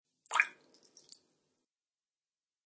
Descarga de Sonidos mp3 Gratis: agua 16.